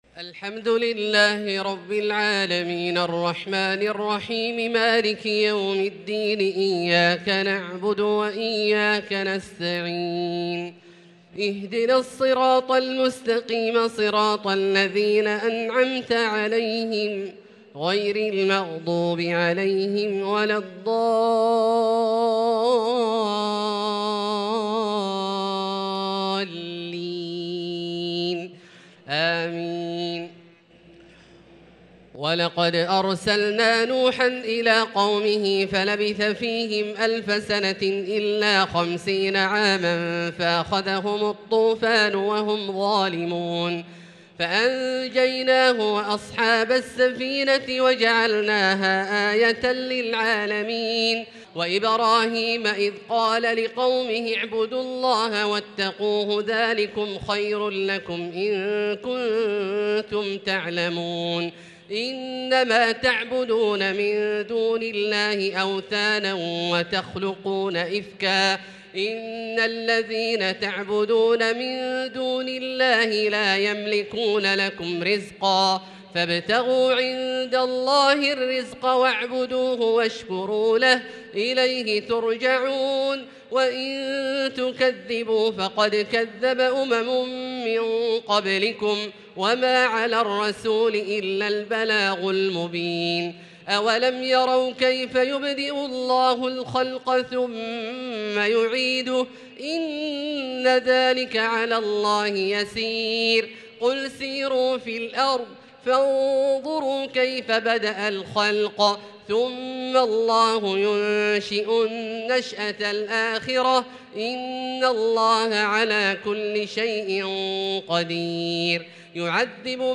صلاة التراويح ليلة 24 رمضان 1443 للقارئ عبدالله الجهني - الثلاث التسليمات الأولى صلاة التراويح